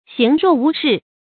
注音：ㄒㄧㄥˊ ㄖㄨㄛˋ ㄨˊ ㄕㄧˋ
行若無事的讀法